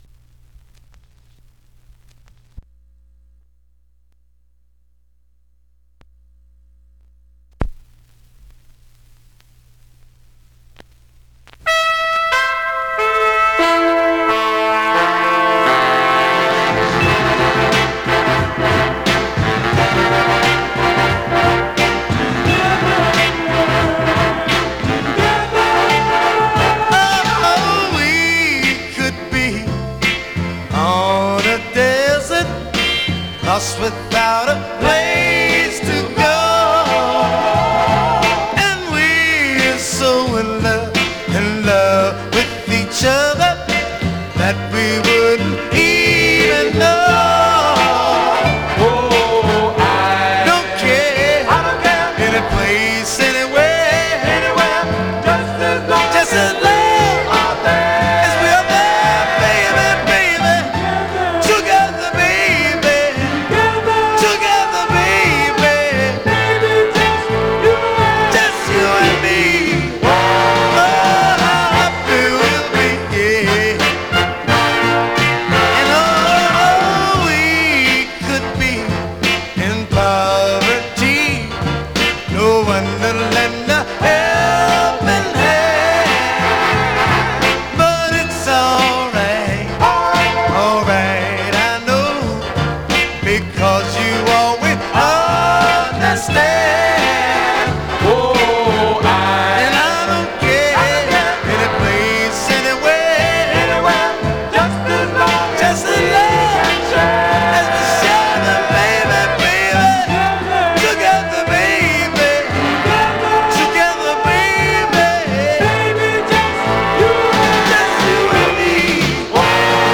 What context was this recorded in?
Some surface noise/wear Stereo/mono Stereo